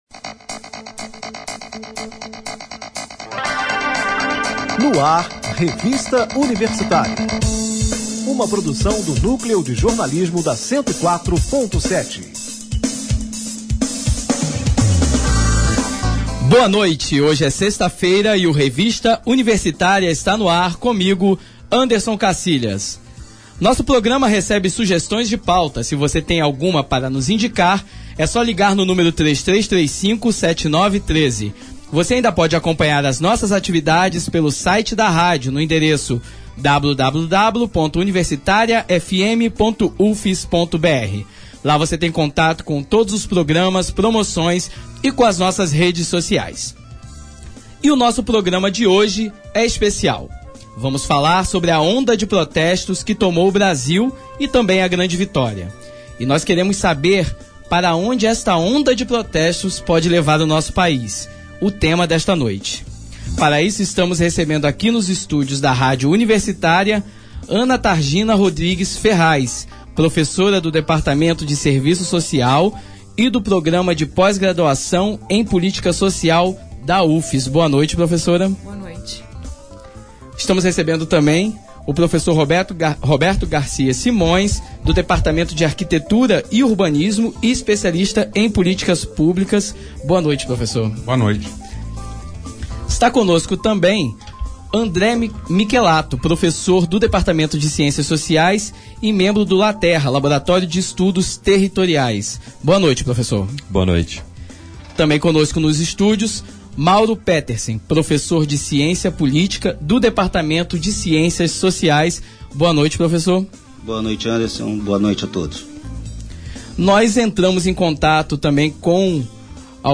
O debate